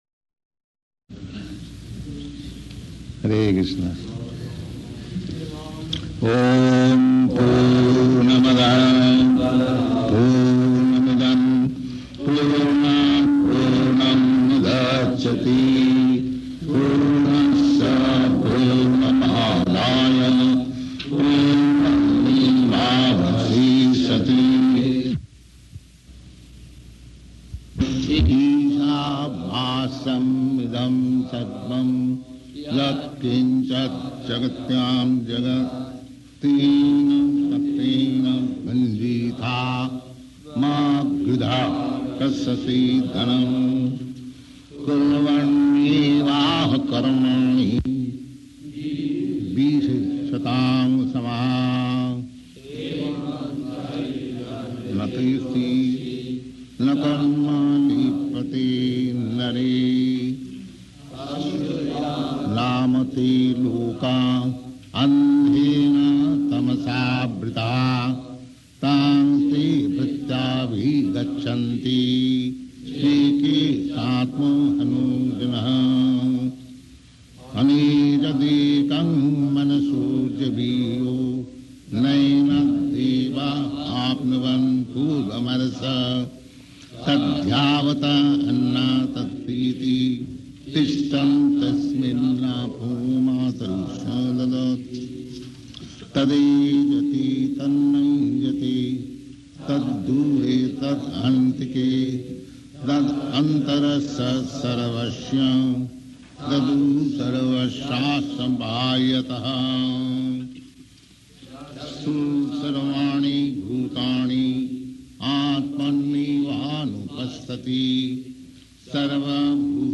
Location: Los Angeles
[Distorted audio]
[leads devotees in chanting]